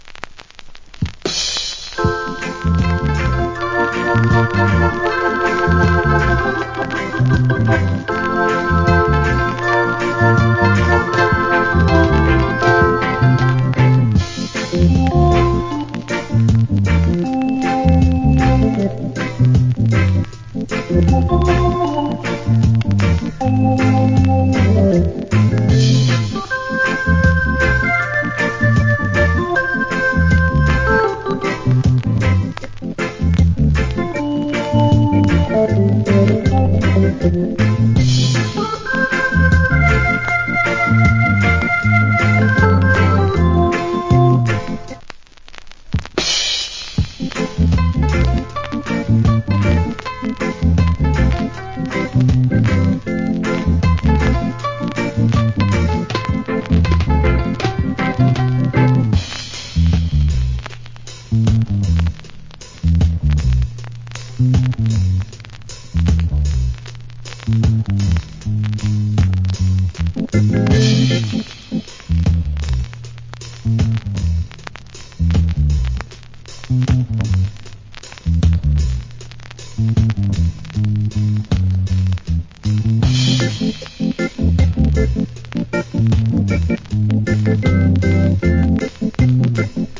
Nice Roots Rock Inst.